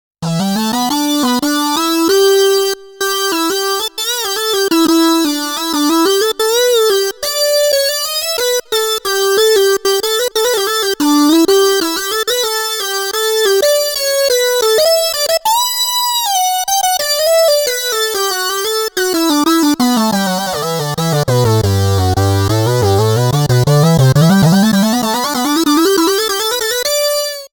Screeching Lead
華やかなシンセリードです。
Screeching-Lead.mp3